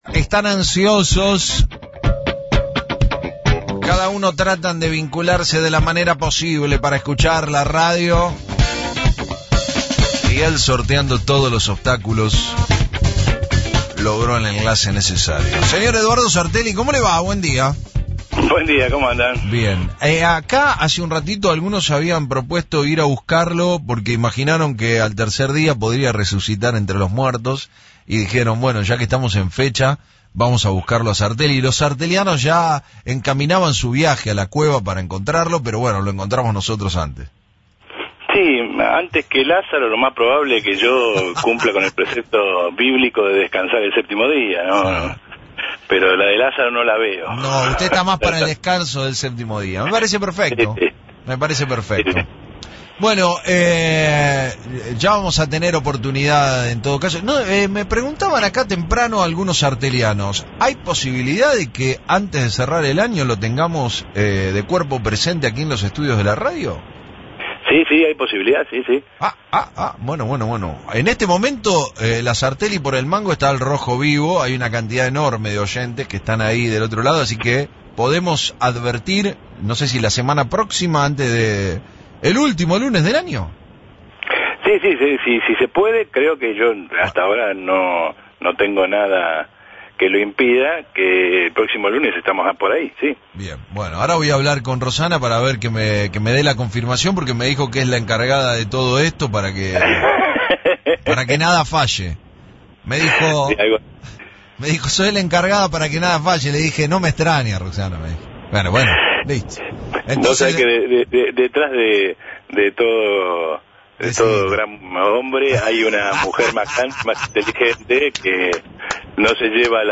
pasó por Frecuencia Zero para hacer una lectura política de las primeras medidas de la nueva gestión de Alberto Fernández.